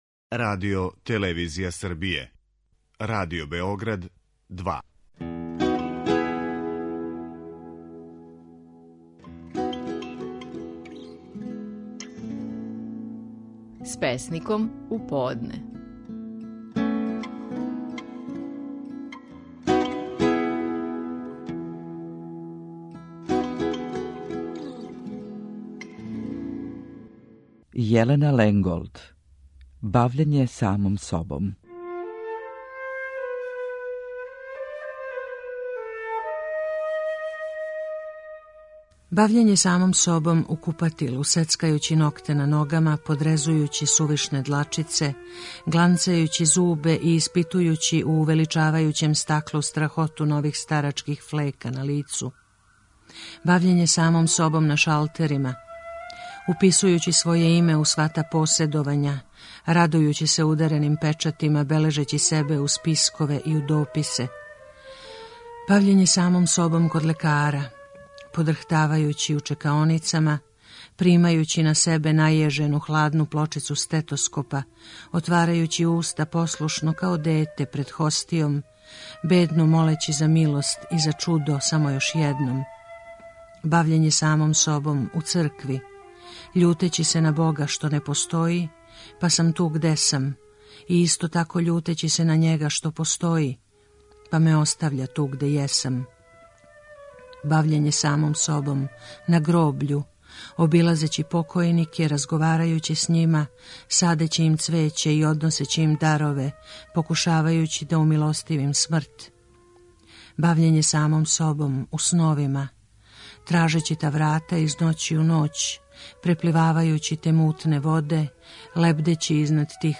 Стихови наших најпознатијих песника, у интерпретацији аутора.
Јелена Ленголд говори стихове песме „Бављење самом собом".